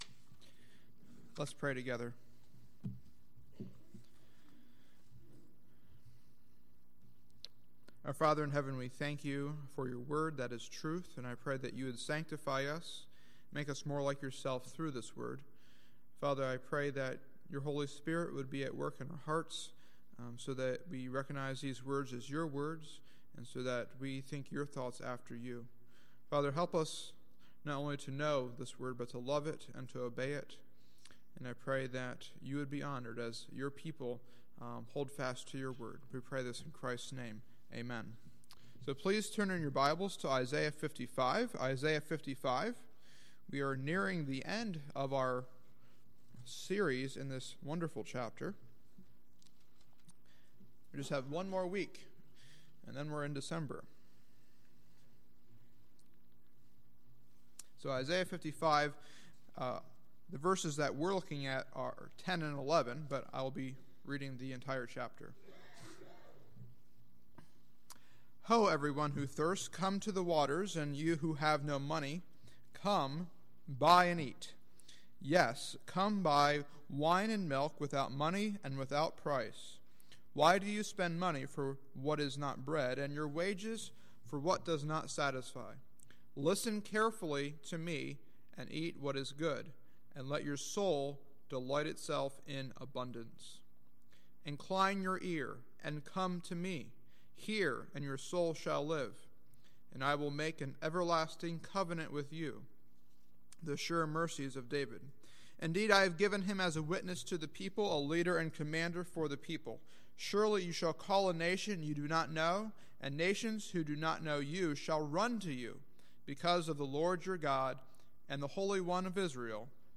Sermons List